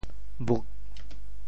吻（肳） 部首拼音 部首 口 总笔划 7 部外笔划 4 普通话 wěn 潮州发音 潮州 bhug4 文 中文解释 吻 <名> (形声。
buk4.mp3